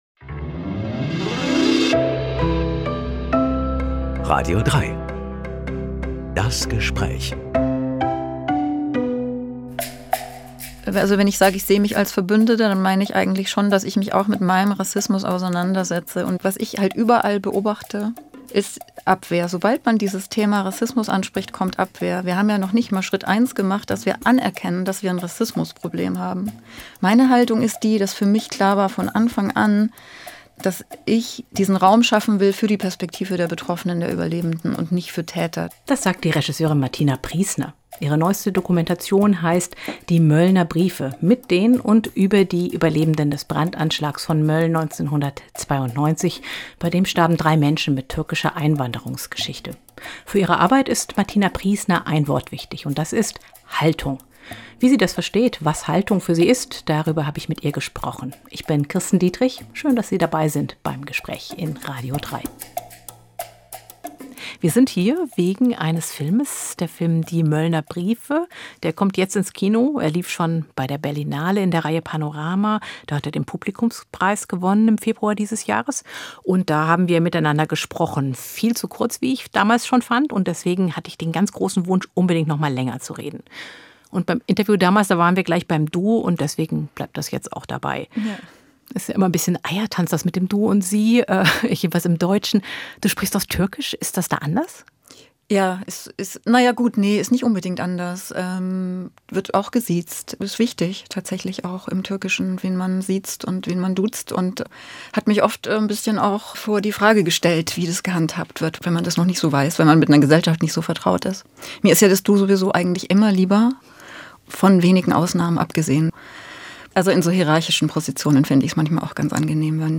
"Die Möllner Briefe" ~ Das Gespräch Podcast